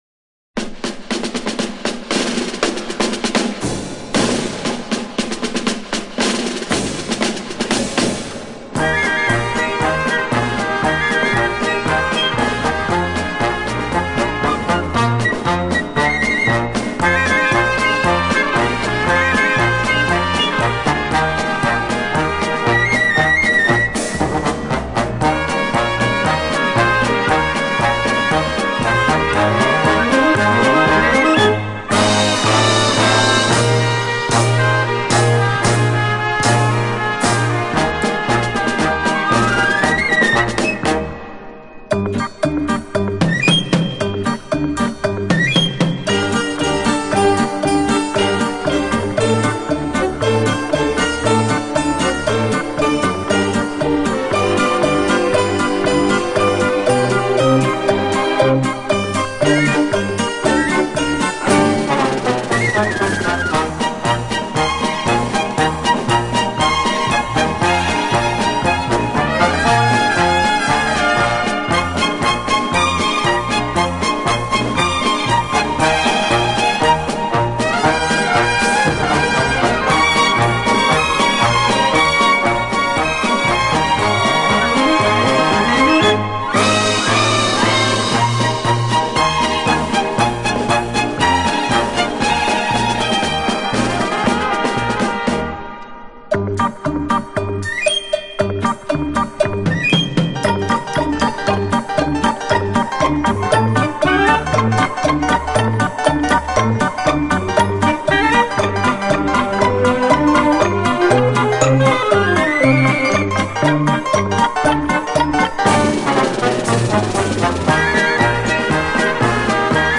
Genre:Easy Listening